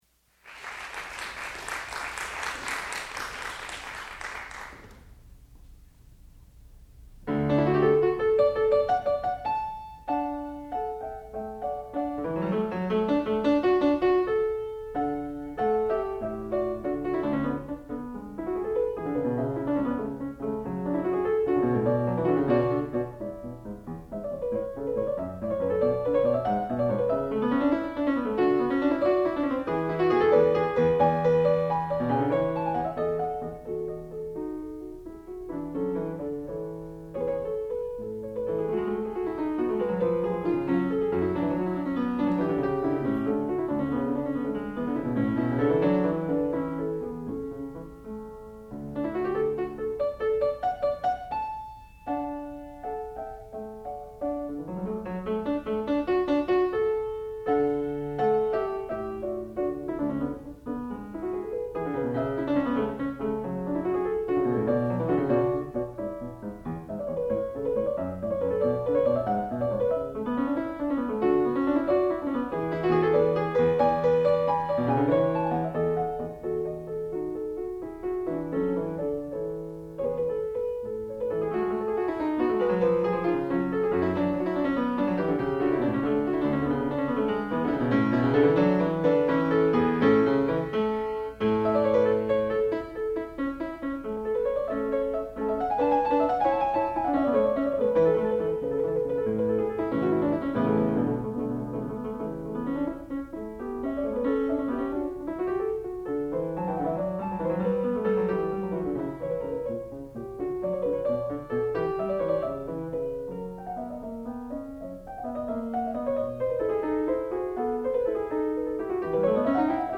sound recording-musical
classical music
Advanced Degree Recital
piano